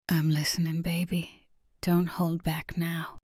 Bold and unfiltered, for those who crave raw insights.